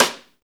SNR XXSTI0PR.wav